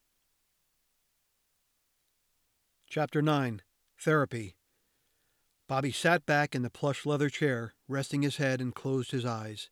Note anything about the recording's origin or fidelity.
Here’s a clip of my new, unprocessed real room tone and recording if you guys can have a listen and let me know if I’m on the right track before I get too far into it.